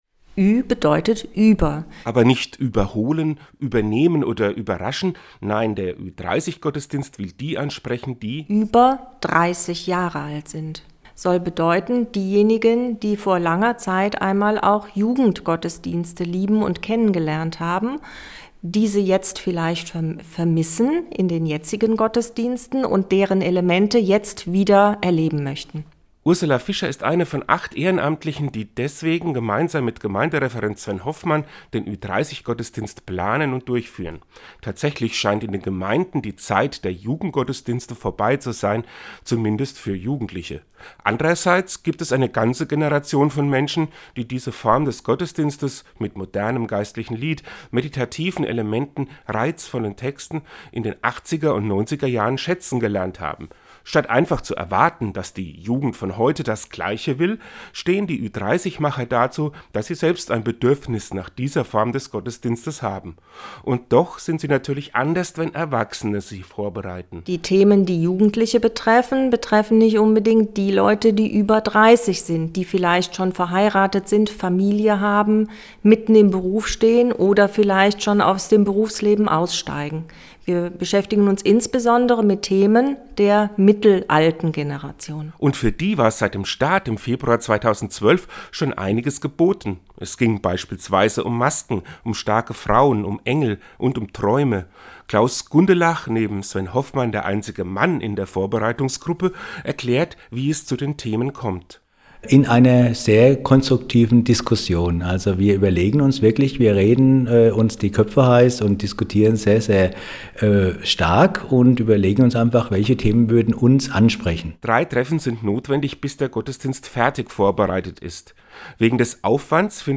Seinen Radiobeitrag können Sie unten Downloaden.